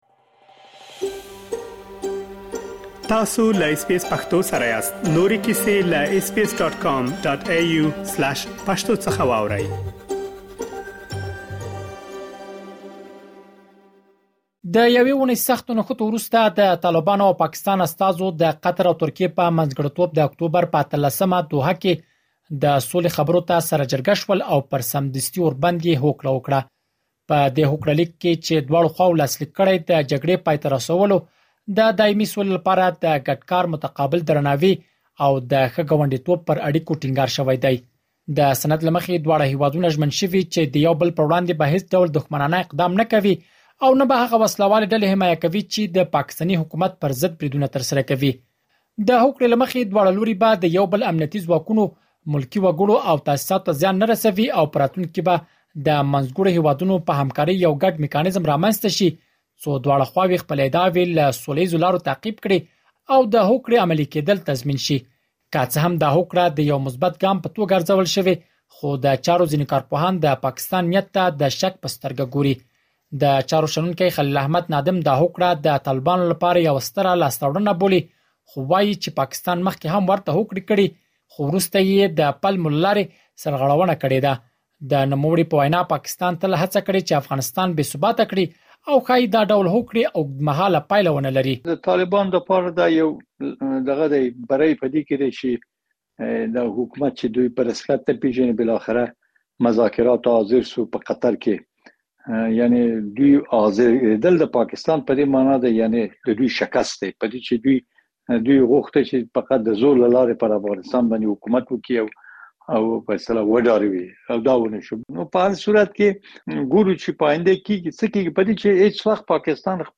مهرباني وکړئ لا ډېر معلومات په رپوټ کې واورئ.